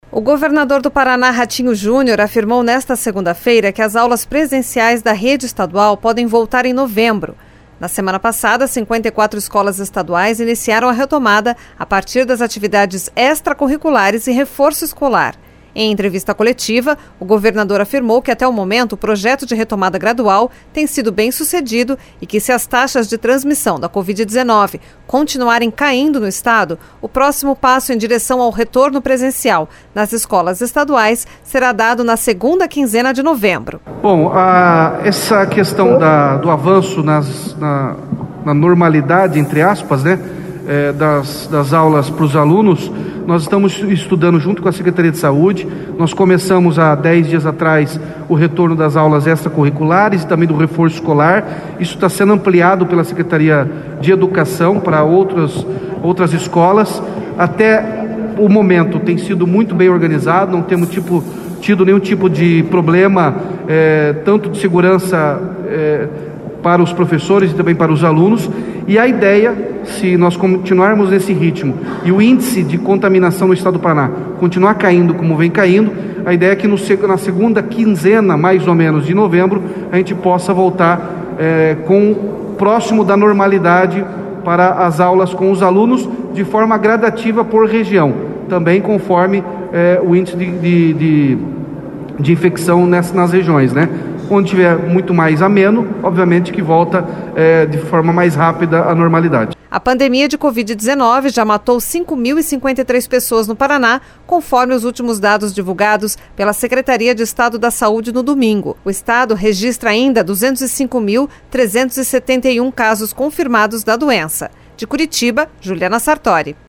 Em entrevista coletiva, o governador afirmou que até o momento o projeto de retomada gradual tem sido bem-sucedido e que, se as taxas de transmissão da Covid-19 continuarem caindo no Estado, o próximo passo em direção ao retorno presencial nas escolas estaduais será dado na segunda quinzena de novembro.